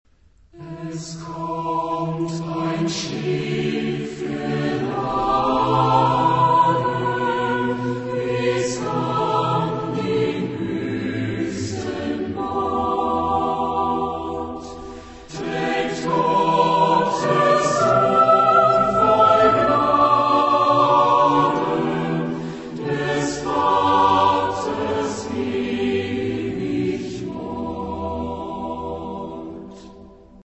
Genre-Style-Forme : Sacré ; Motet
Type de choeur : SATB  (4 voix mixtes )
Tonalité : sol majeur